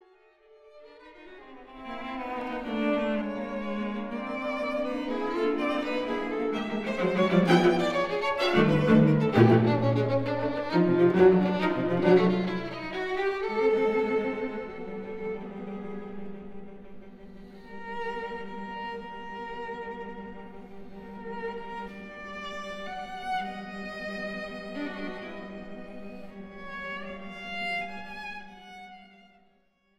Lively, Graceful